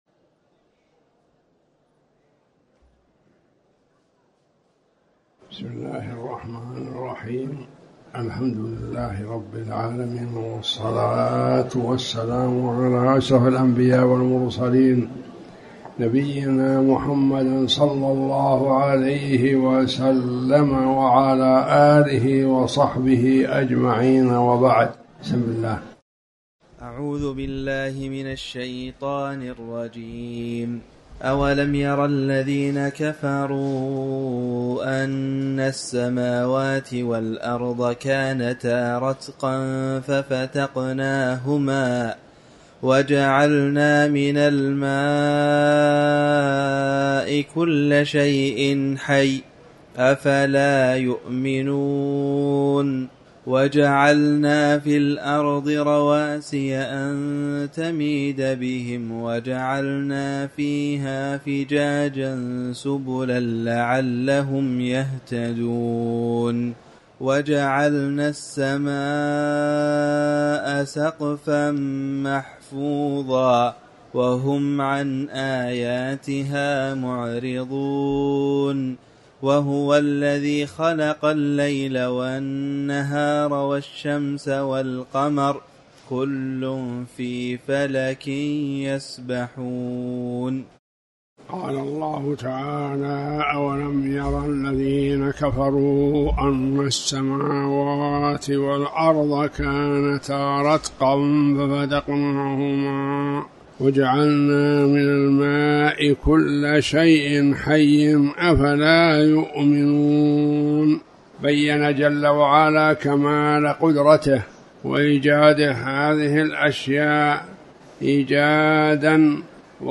تاريخ النشر ٧ جمادى الآخرة ١٤٤٠ هـ المكان: المسجد الحرام الشيخ